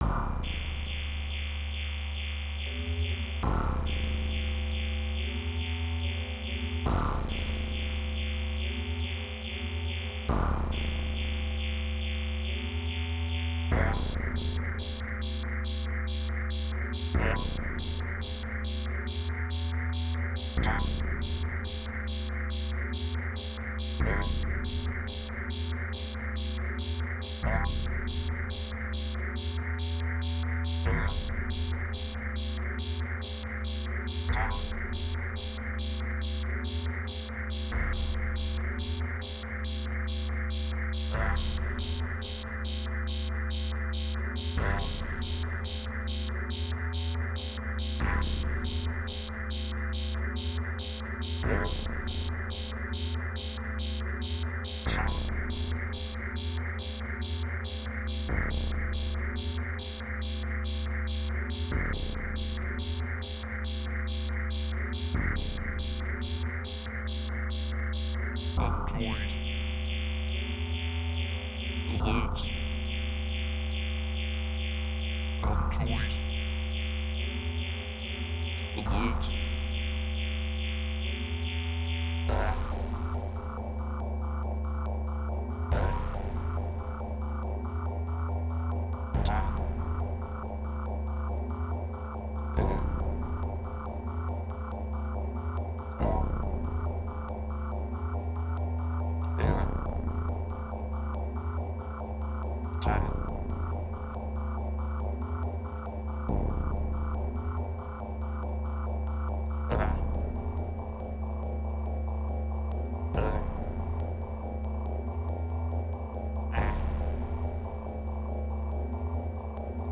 Ambisonic
Ambisonic order: H (3 ch) 1st order horizontal